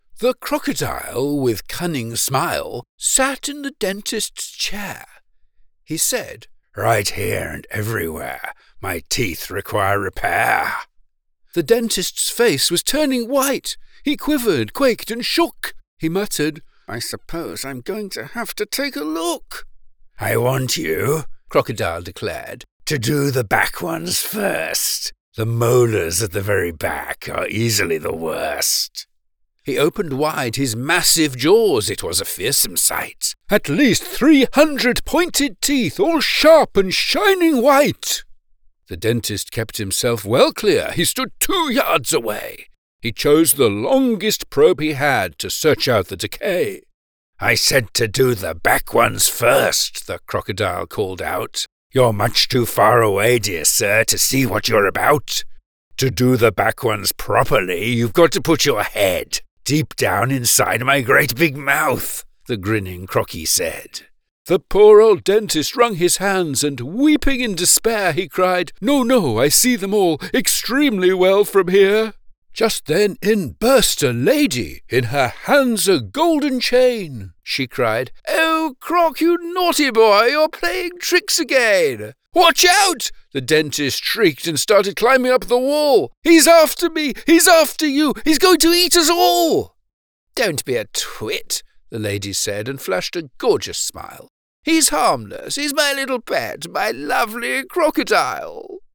British Children's Poetry Narrator:
Warm, Clear, and so Wonderfully Told!
The younger the audience the more over-the-top a performance may need to be and the more fun I and my audience can have with it!